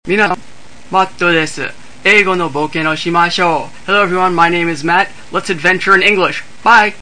インタビュー音声公開
インタビュー音声